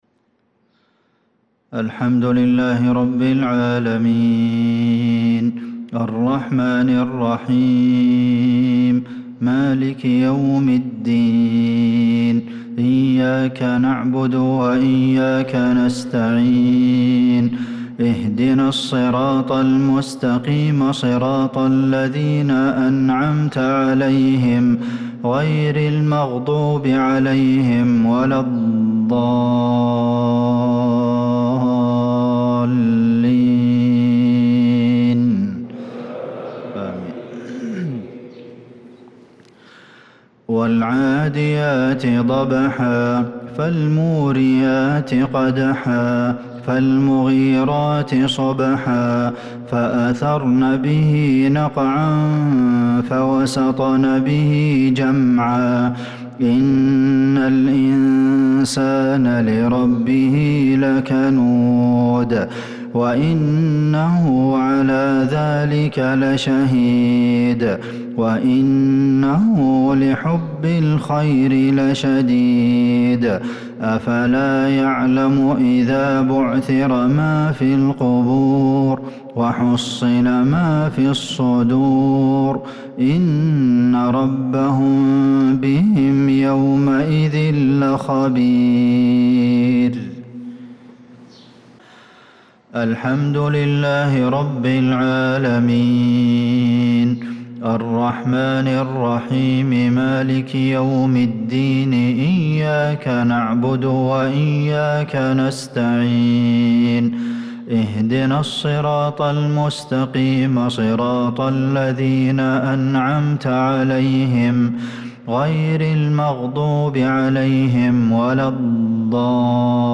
صلاة المغرب للقارئ عبدالمحسن القاسم 12 ذو الحجة 1445 هـ
تِلَاوَات الْحَرَمَيْن .